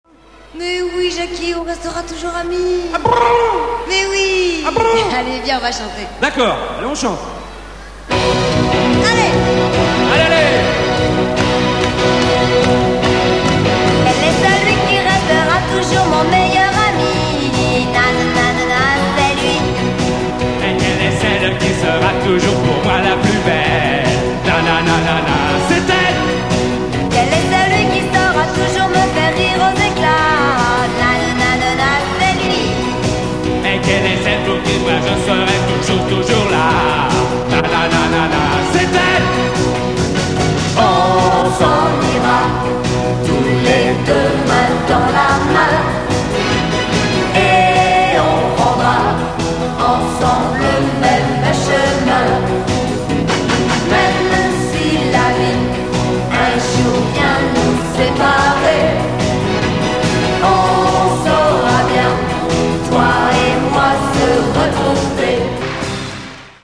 Les "live"